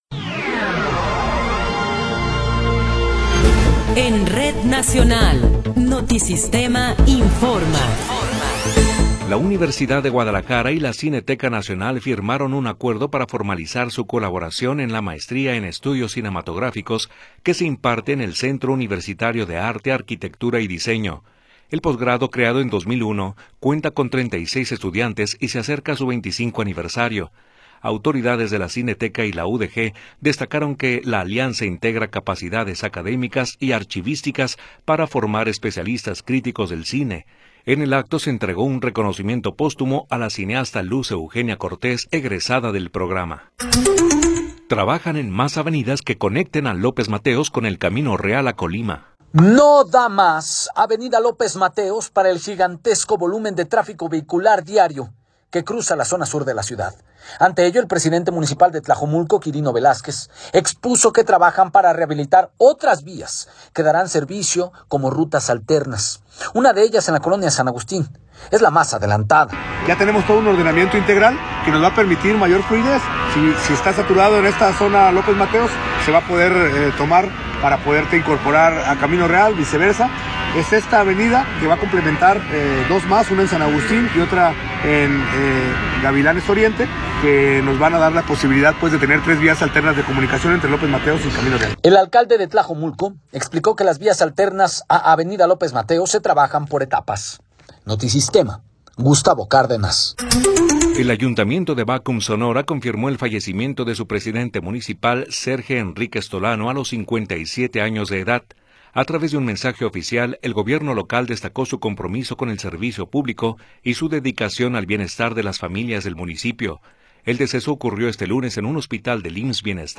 Noticiero 19 hrs. – 19 de Enero de 2026
Resumen informativo Notisistema, la mejor y más completa información cada hora en la hora.